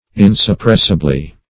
-- In`sup*press"i*bly , adv.
insuppressibly.mp3